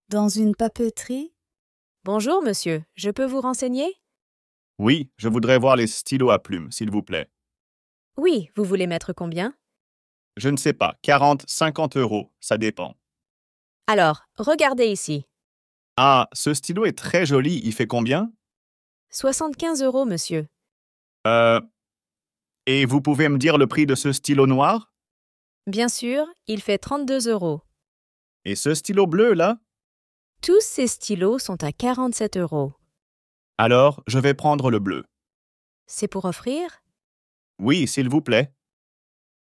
Dialogue FLE - dans une papeterie